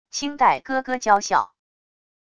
青黛咯咯娇笑wav音频